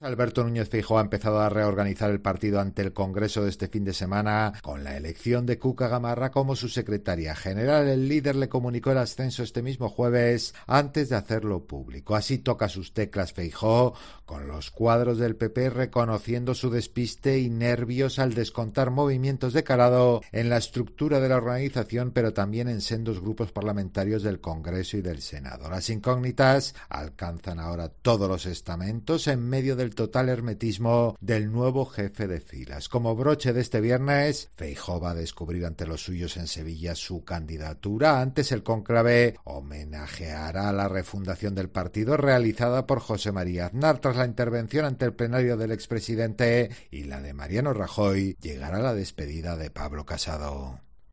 Desde primera hora de la mañana, Cope se ha desplazado a una gasolinera de San Sebastián de los Reyes, en Madrid.